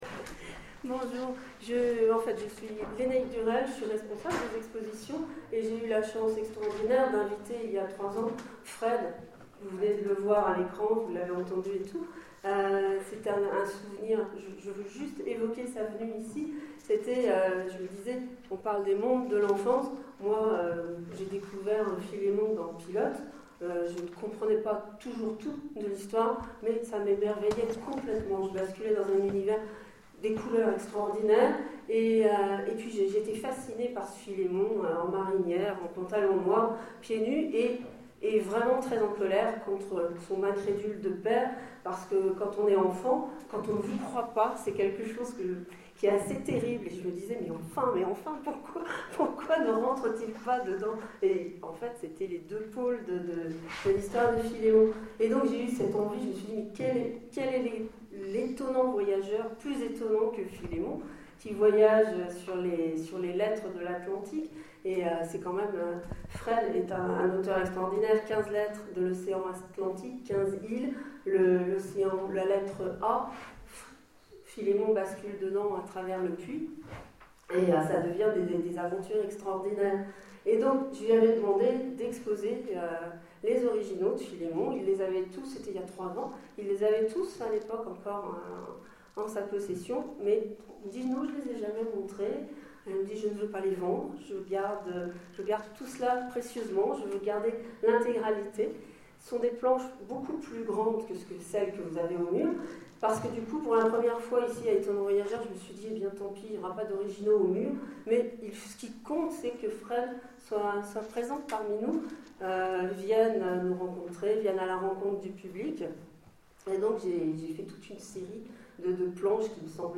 Etonnants Voyageurs 2013 : Conférence Les mondes de l'enfance